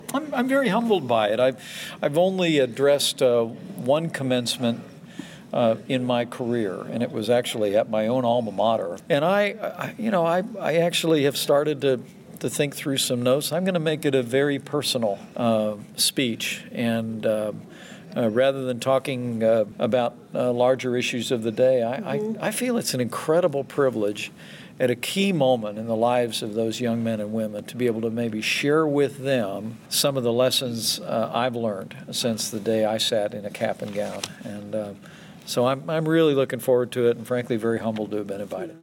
Governor Mike Pence says he is humbled to speak at the graduation ceremony for Indiana Wesleyan University April 30.